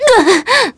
Lewsia_B-Vox_Damage_kr_01.wav